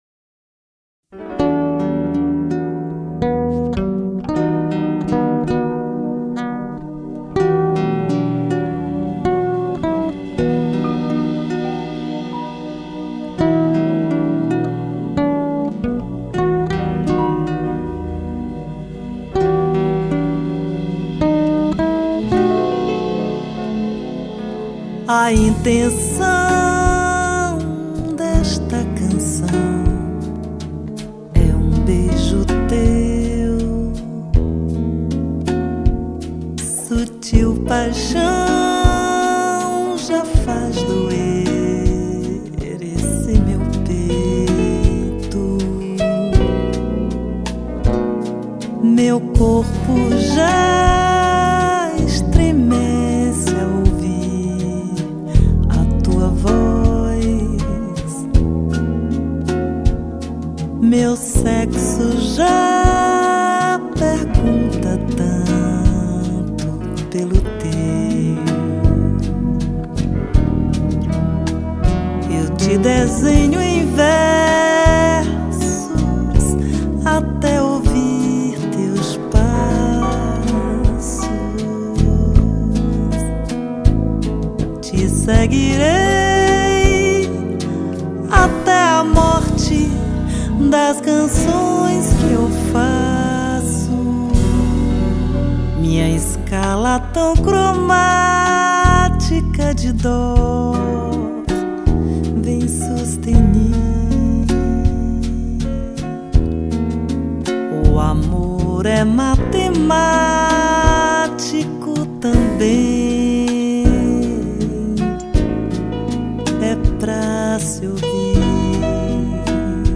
1573   05:19:00   Faixa:     Bossa nova
Baixo Elétrico 6
Bateria
Violao Acústico 6
Piano Acústico, Teclados